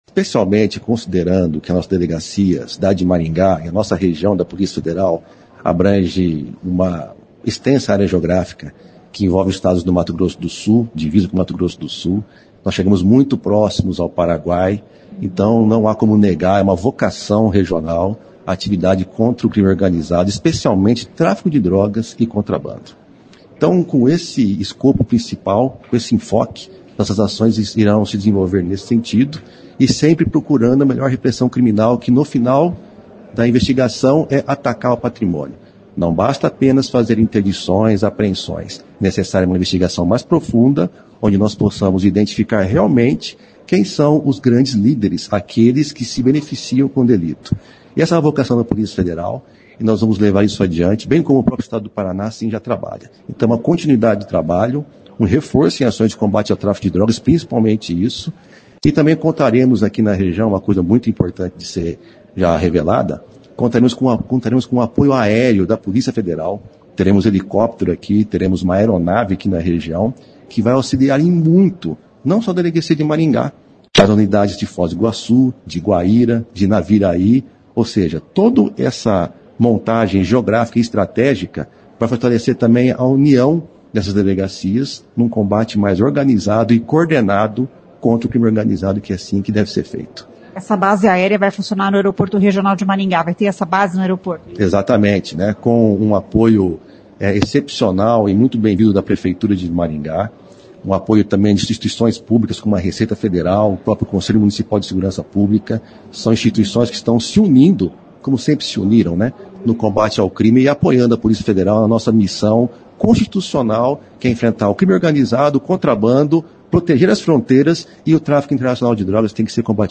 Ele disse que irá dar continuidade ao trabalho que a gestão anterior vinha realizando para reforçar a segurança nesta região de fronteira. O destaque é para a base aérea da PF que deve começar a operar daqui 60 dias. Ouça a entrevista: